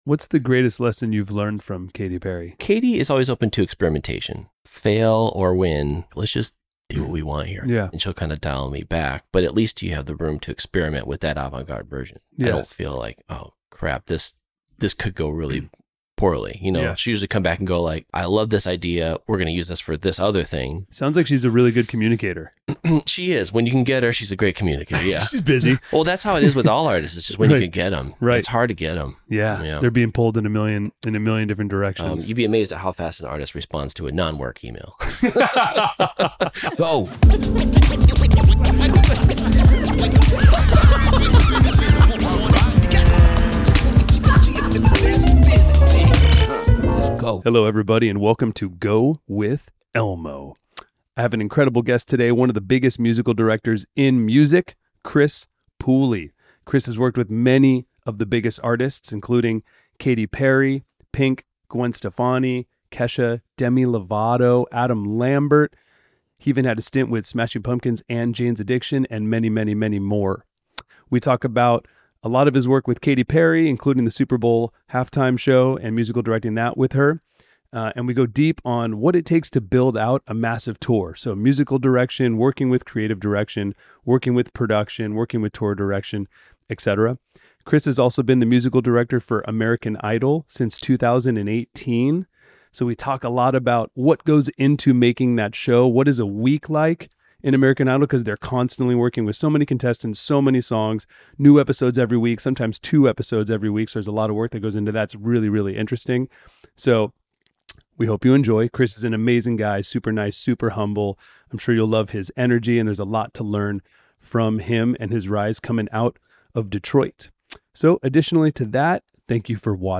We hope you enjoy the conversation as much as we did.